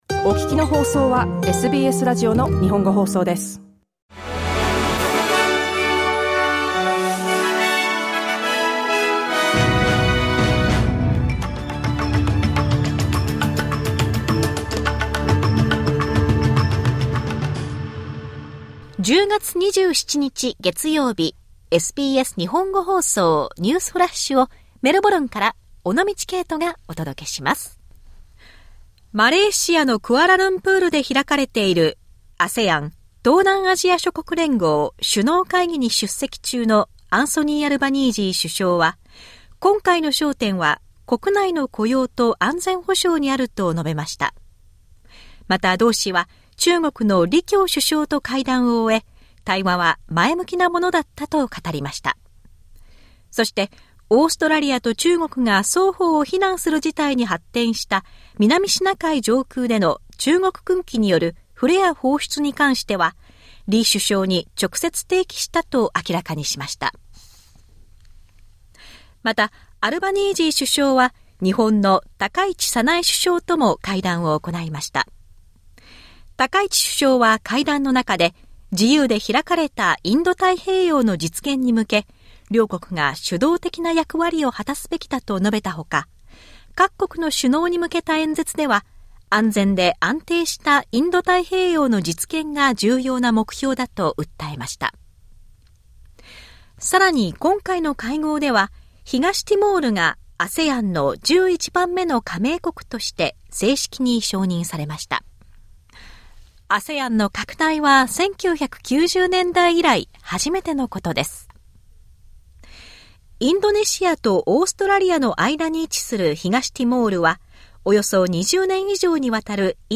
SBS日本語放送ニュースフラッシュ 10月27日 月曜日